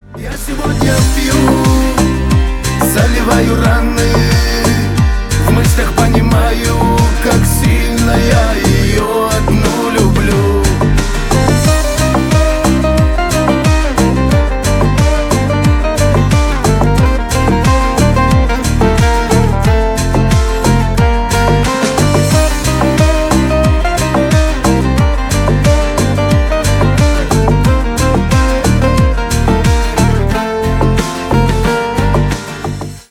кавказские
шансон